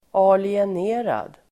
Ladda ner uttalet
Uttal: [alien'e:rad]